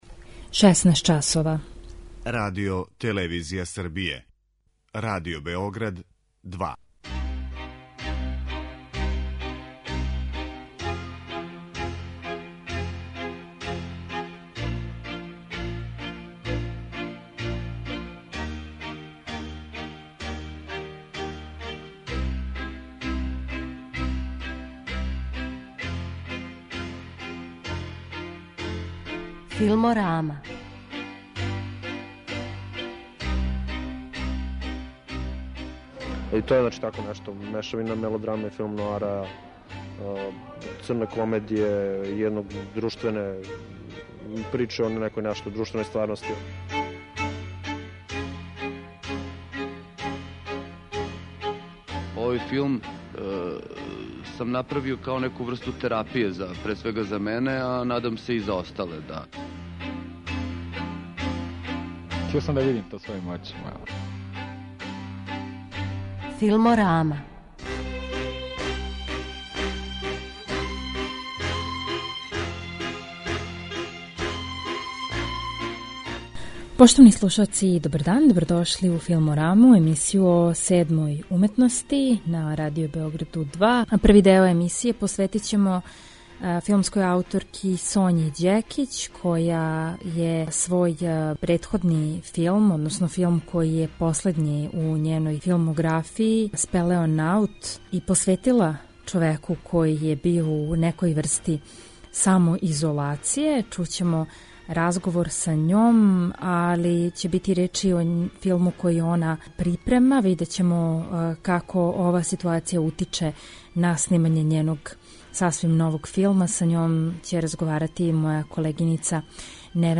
Емисија о филму